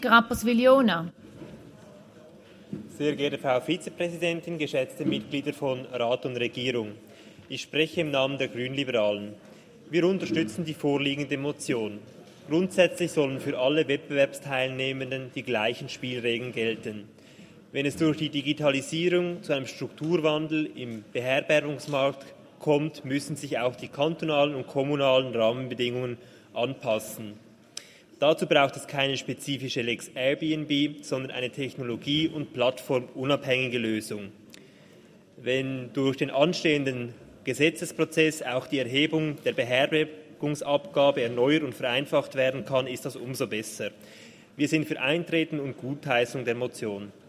Session des Kantonsrates vom 19. bis 21. September 2022